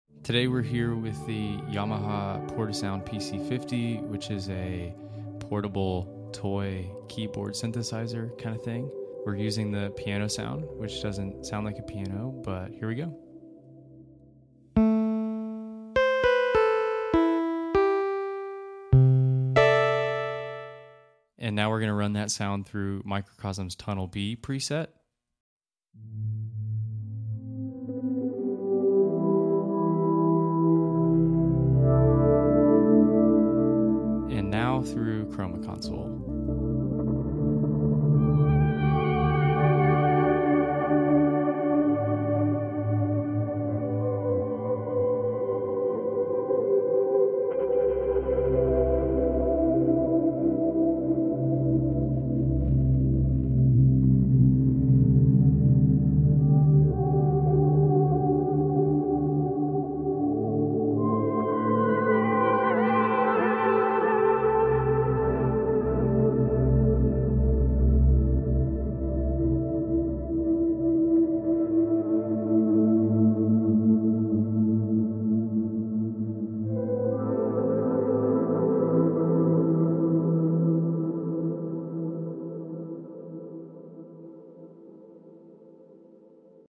Both Microcosm and Chroma Console are capable of transforming your instrument far beyond its original sound. To demonstrate that, we hooked up a vintage Yamaha Portasound PC-50 to both of them to create cinematic sounds from the Portasound's endearing attempt at a "piano" sound.